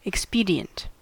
Ääntäminen
US : IPA : [ɪk.ˈspi.di.ənt]